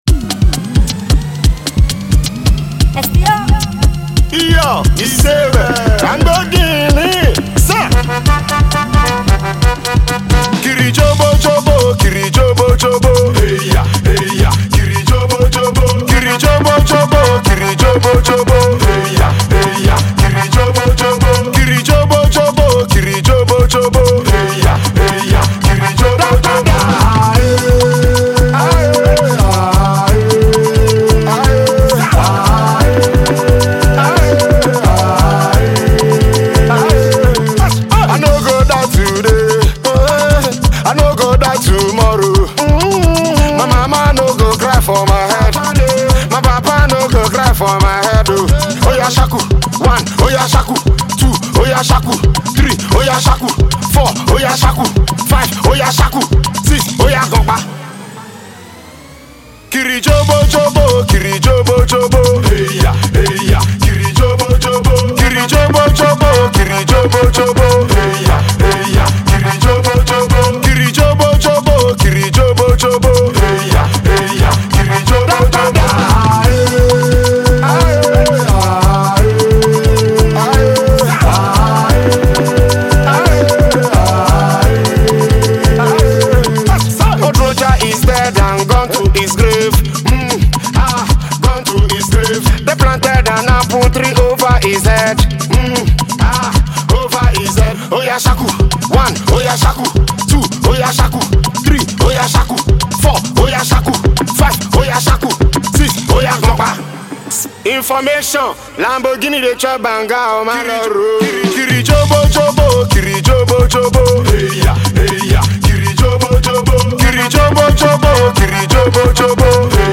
Afro Dancehall star